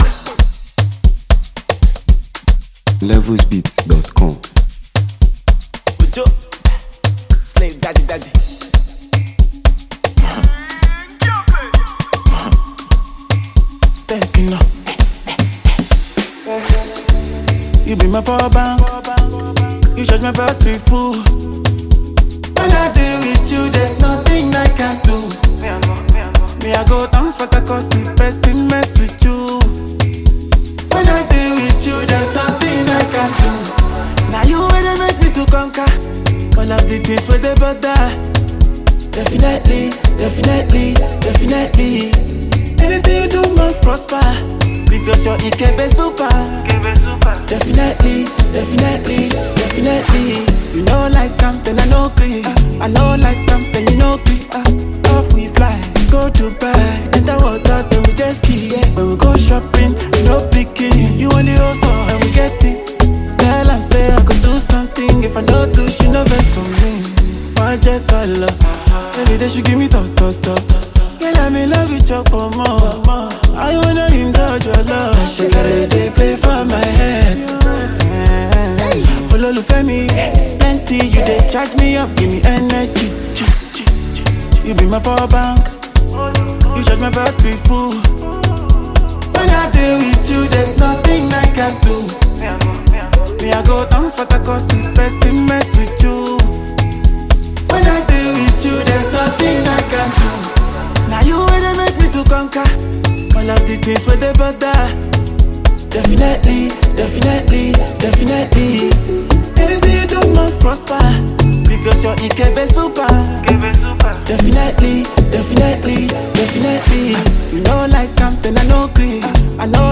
With its energetic vibe and replay-worthy sound